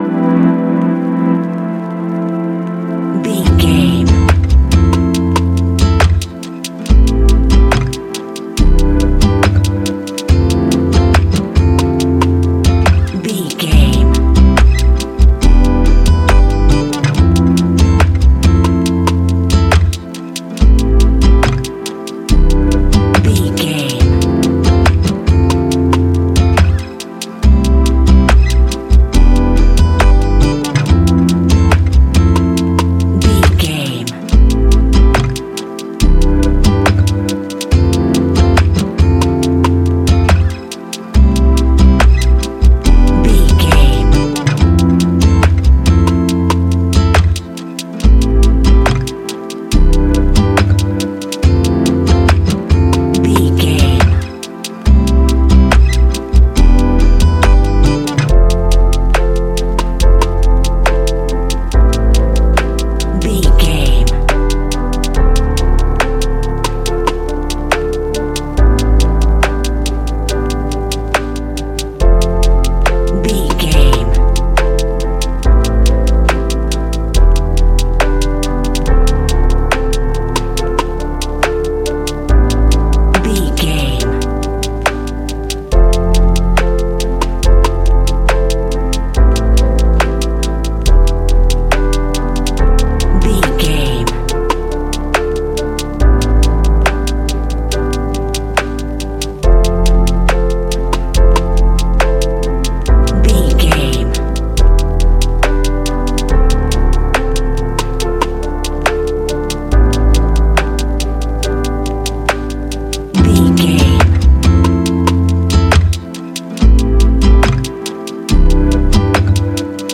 Ionian/Major
chilled
laid back
Lounge
sparse
new age
chilled electronica
ambient
atmospheric
morphing
instrumentals